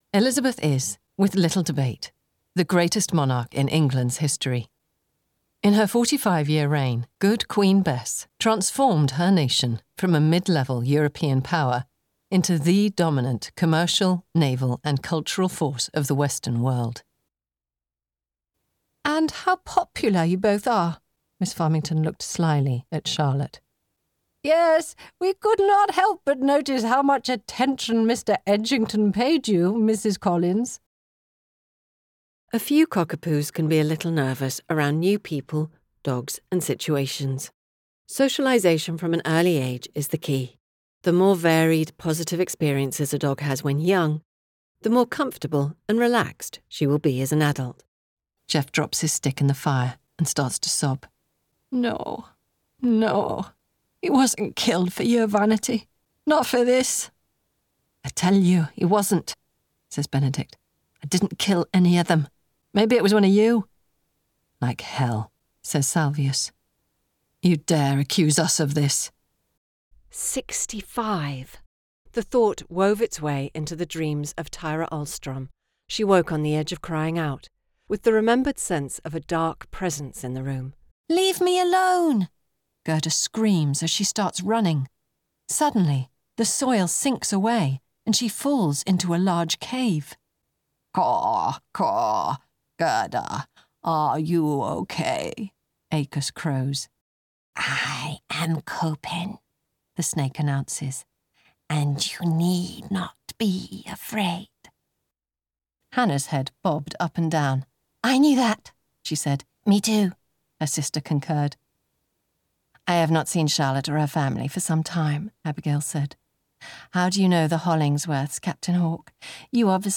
Narration - EN